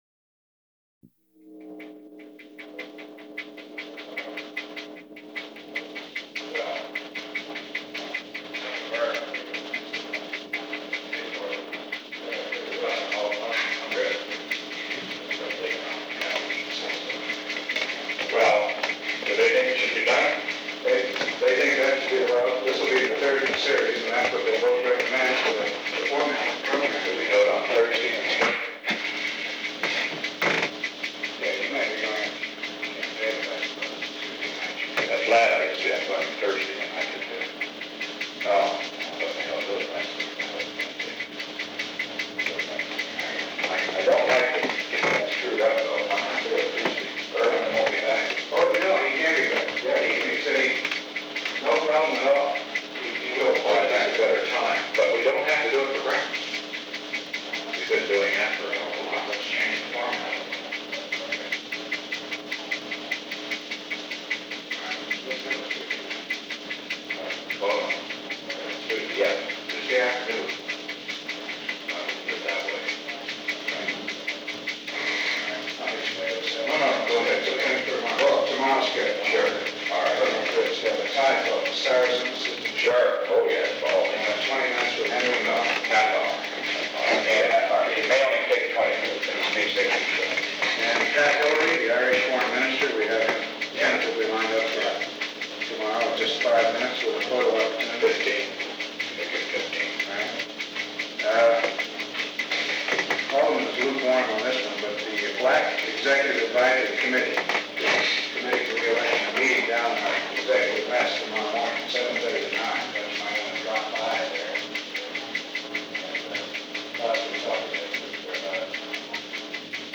Secret White House Tapes
Conversation No. 364-1
Location: Executive Office Building
The President met with Alexander P. Butterfield.